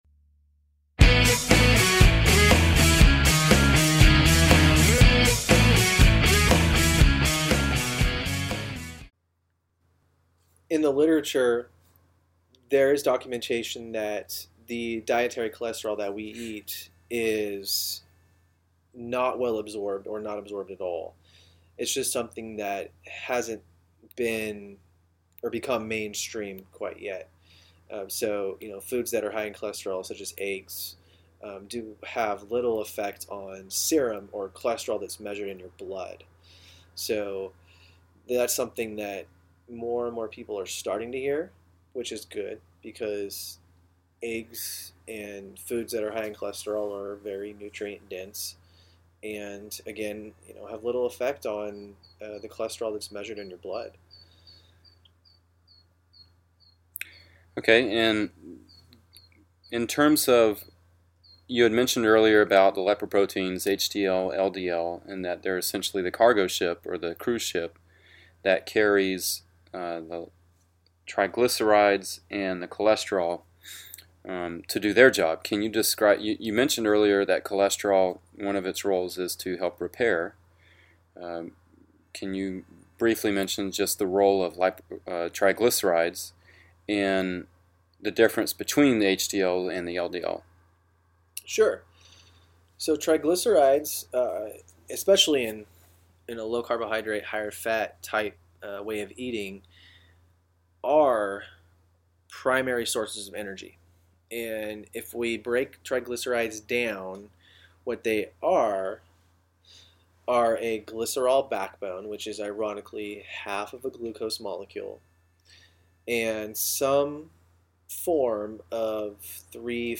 Today’s interview is a change of pace from our usual foremat that many of you have come to enjoy.